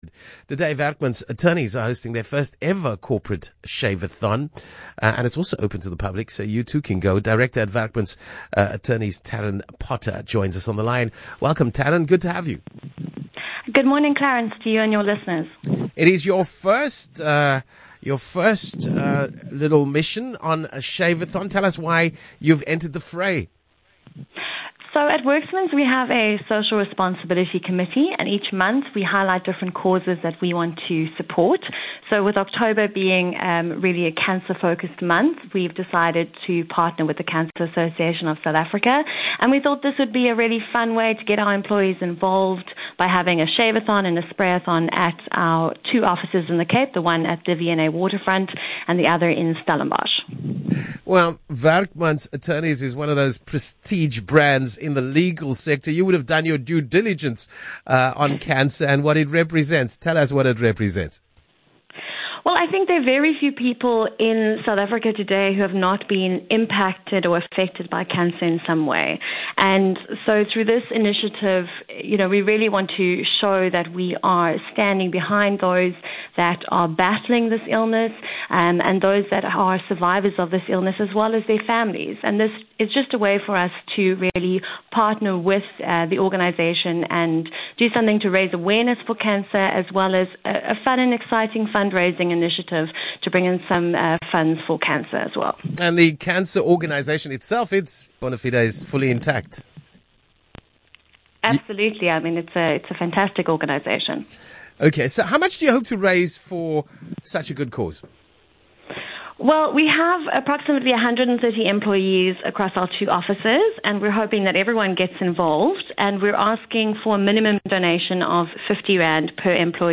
speaks to Cape Talk